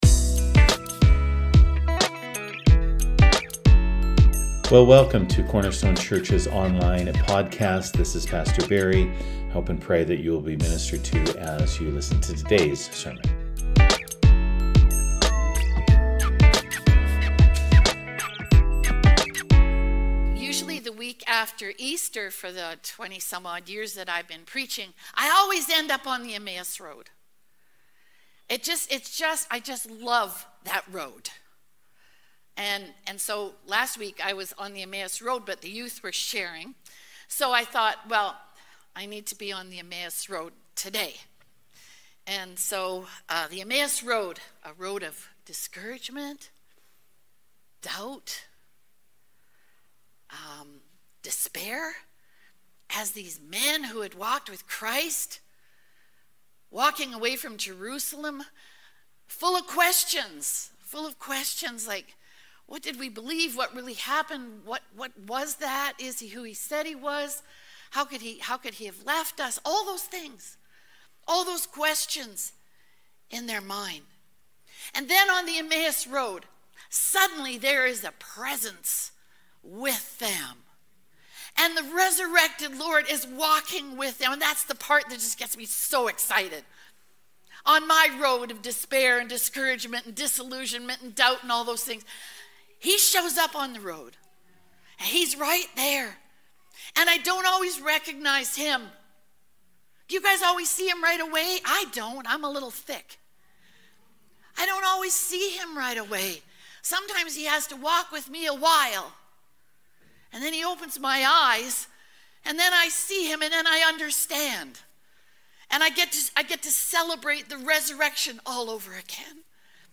Sermons | Cornerstone Church